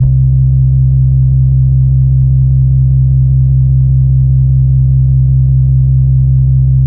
GuitarBass_YC.wav